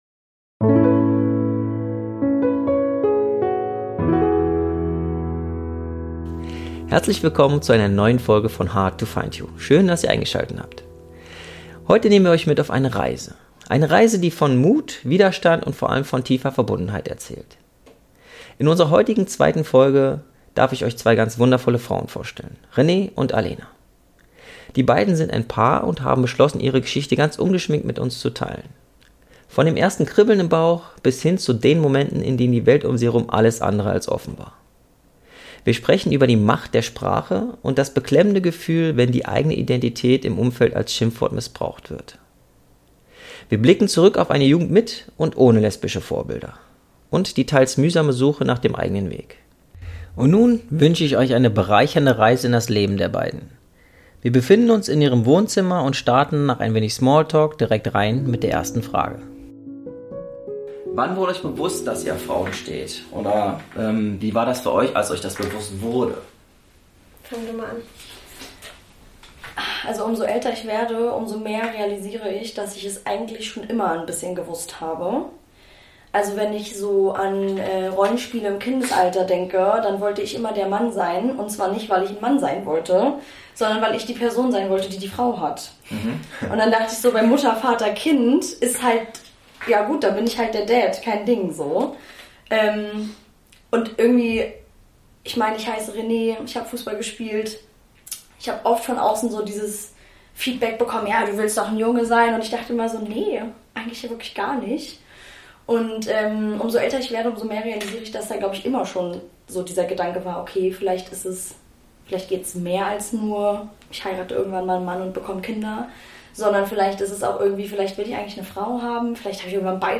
Euch erwarten kurze entspannte Interviews, als auch längere Gespräche die mehr in die Tiefe gehen.